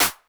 Snares
snr_75.wav